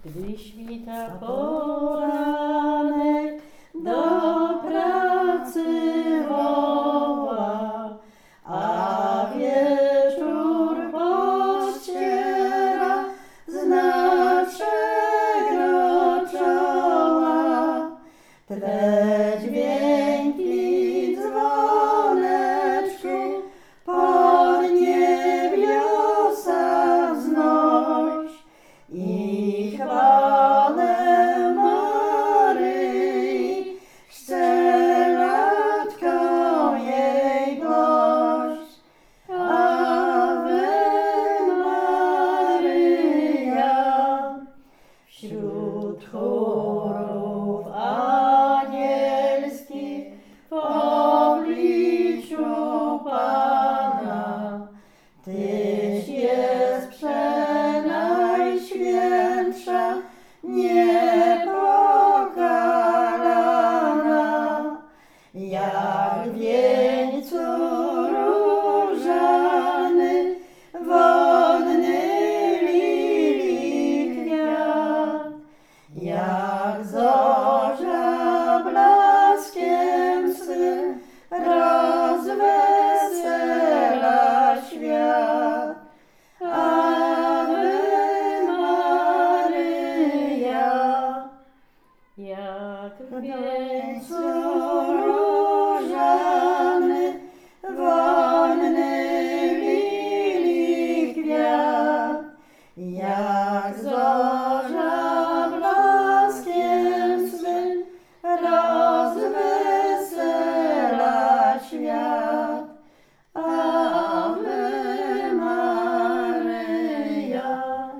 Pieśń
Nagranie wykonane podczas wywiadu